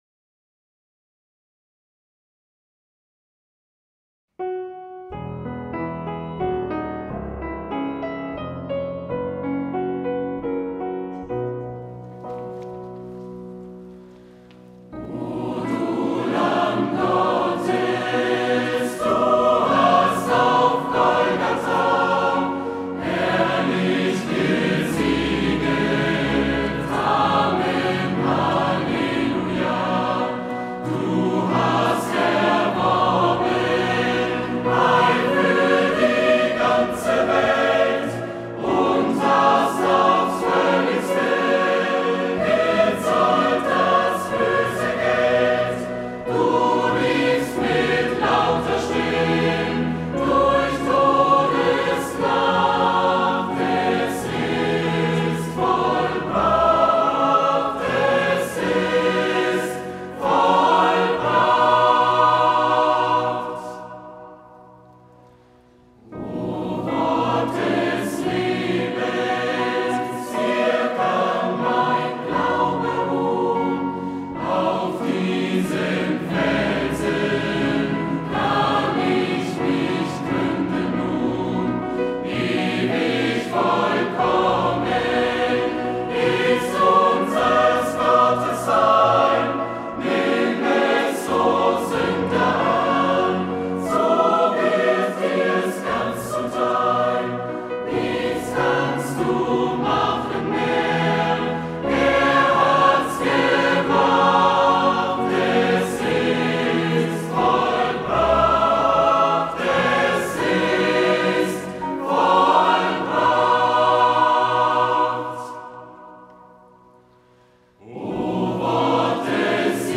Passionssingen 2026